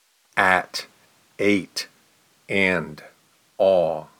Vowel-shifts_at.mp3